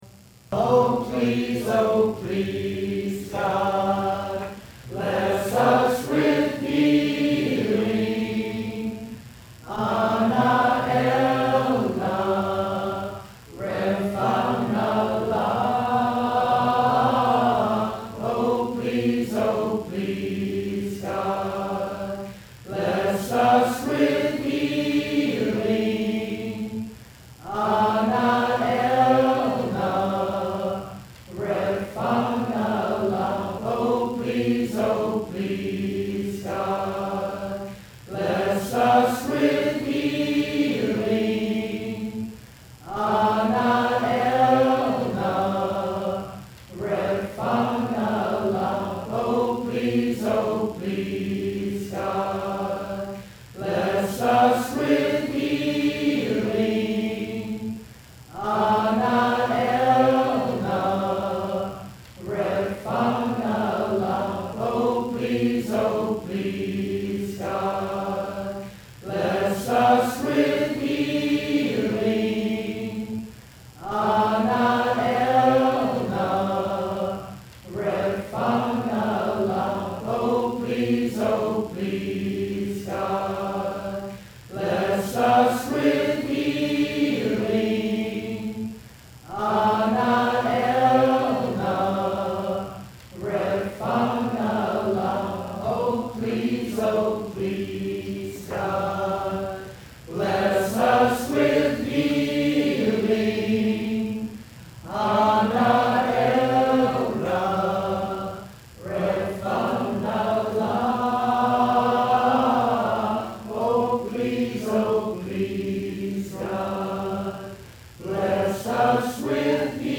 in several Hebrew chants during a Sunday morning worship service on February 17, 2008.*
*All chants were recorded live at Mill Valley Community Church, Mill Valley, California, February 17, 2008.